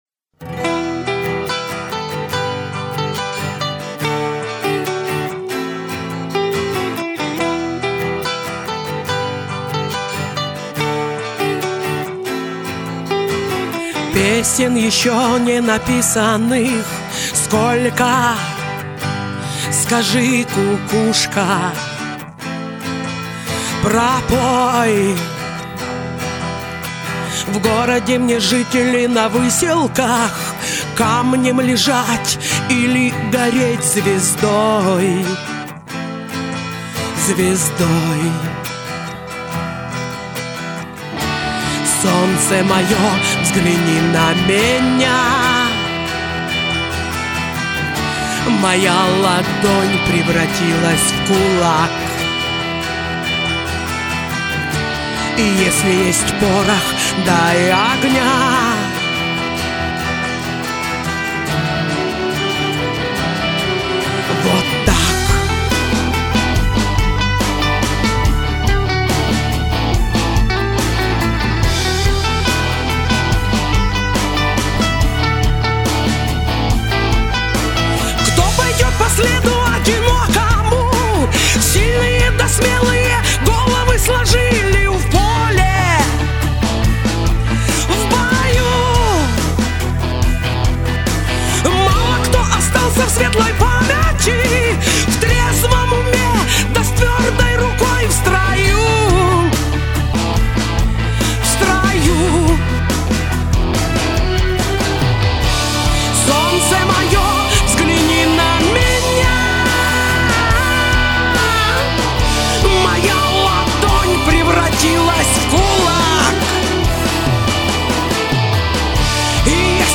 очень сильно, слышно сразу, что петь начали не вчера